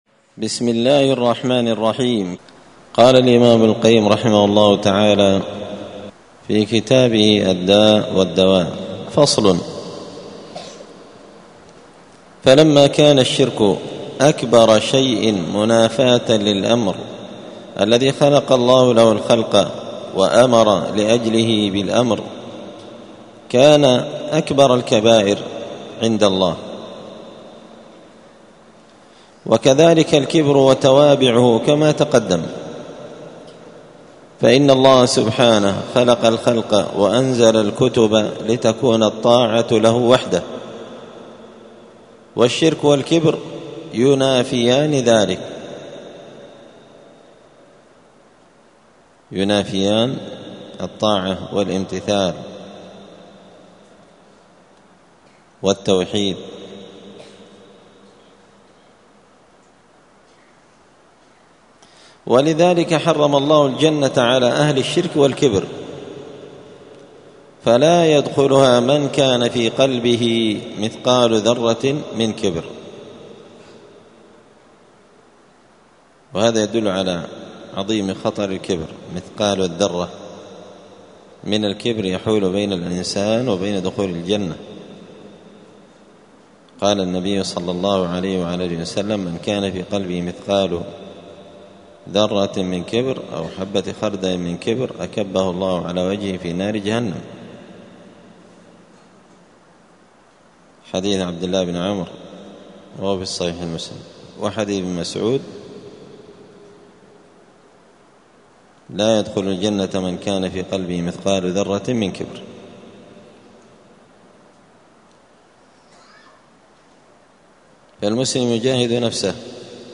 *الدرس الرابع والستون (64) فصل الشرك والكبر*